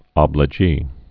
(ŏblə-jē)